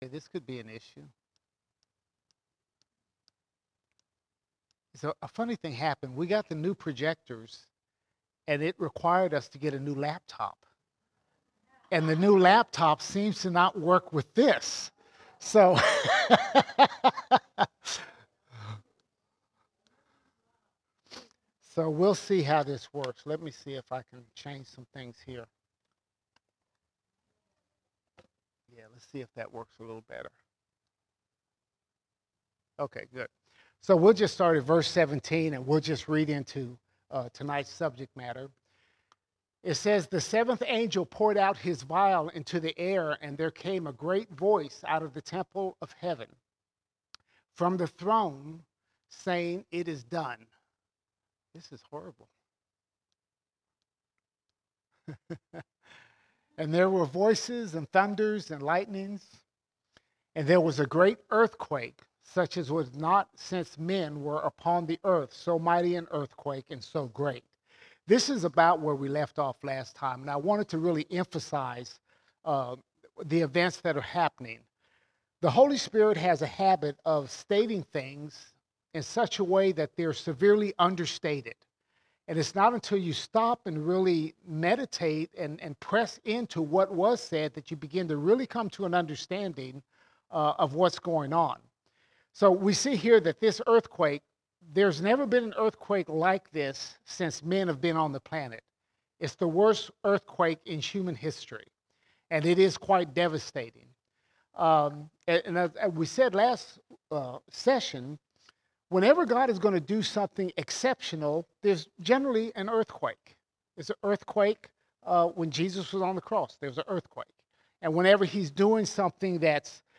14 November 2024 Series: Revelation All Sermons Revelation 16:18 to 17:18 Revelation 16:18 to 17:18 We see the rise of false religion like today's ecumenical movement.